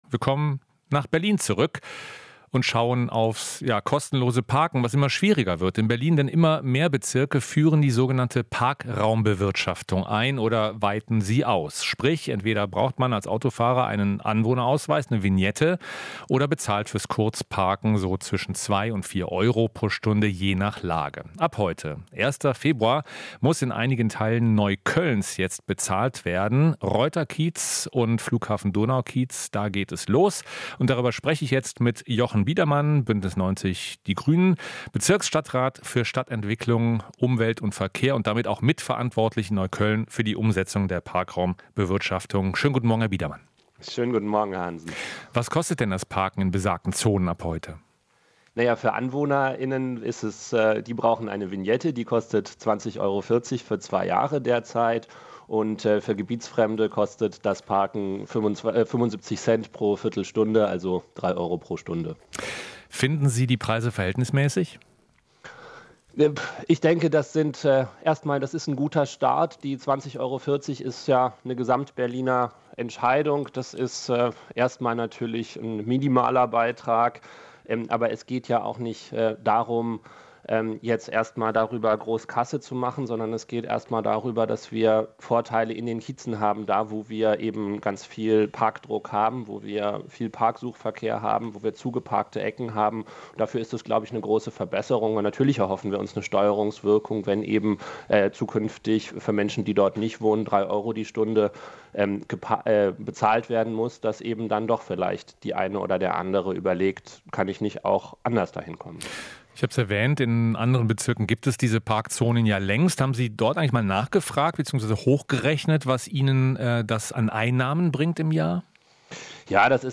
Interview - Neuköllner Stadtrat: Mit Parktickets gegen zugeparkte Ecken